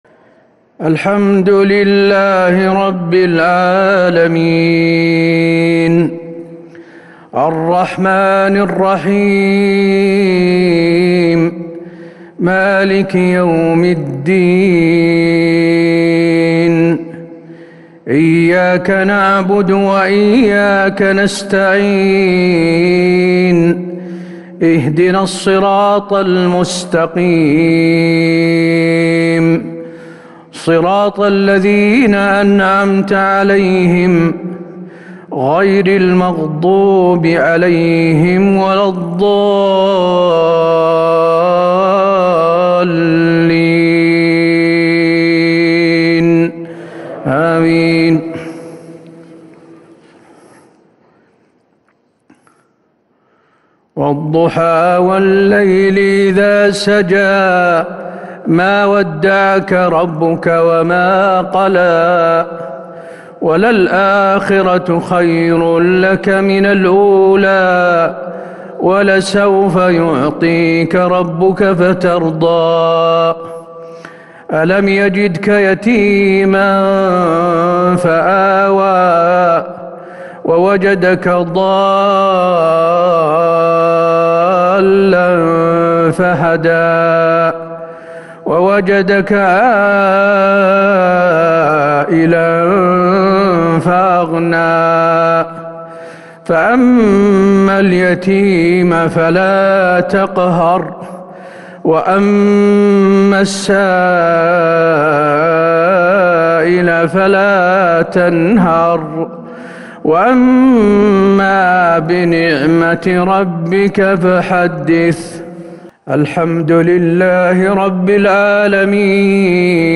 مغرب الأحد 8-8-1442هـ سورتي الضحى و الشرح | Maghrib prayer from Surat Ad-Dhuhaa"" Ash-Sharh 21/3/2021 > 1442 🕌 > الفروض - تلاوات الحرمين